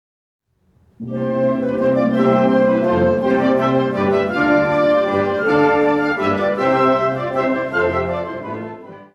Categorie Harmonie/Fanfare/Brass-orkest
Bezetting Ha (harmonieorkest)